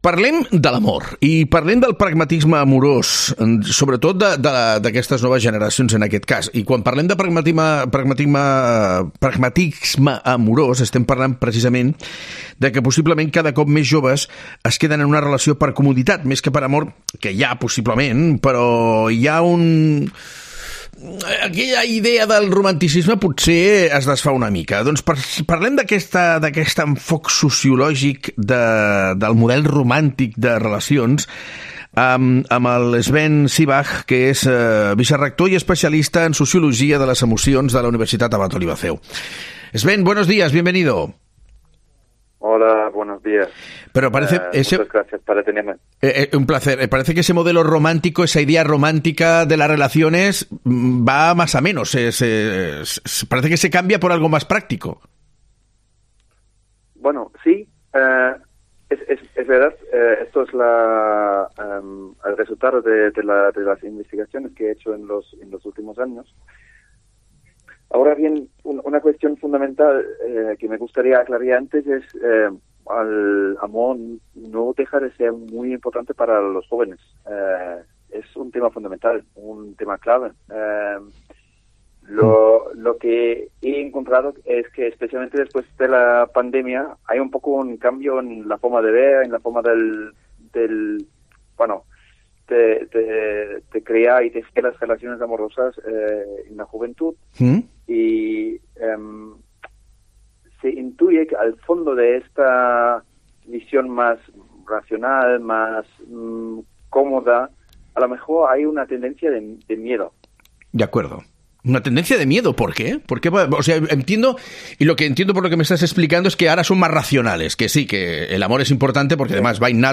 quien ha comentado en una entrevista en La Cope que la juventud es menos emotiva en el amor que generaciones precedentes.